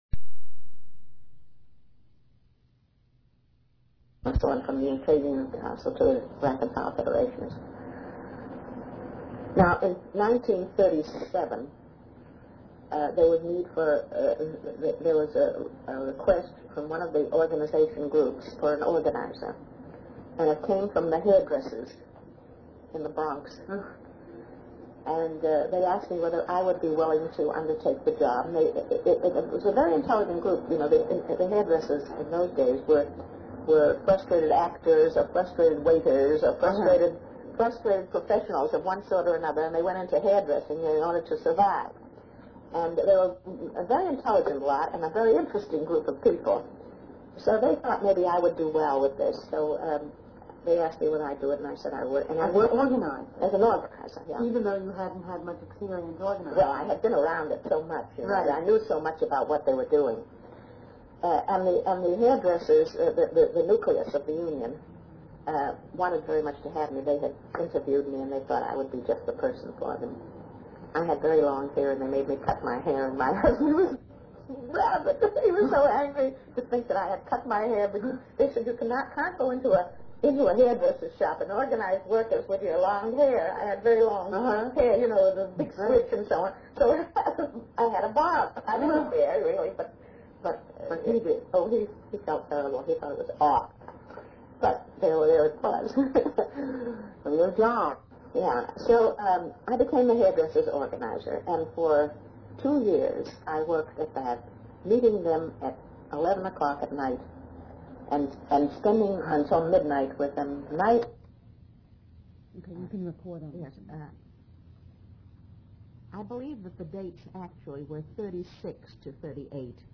The flow of this interview is somewhat confusing since a portion of it was a re-recording of the initial interview. That interview is being played and new material is being added.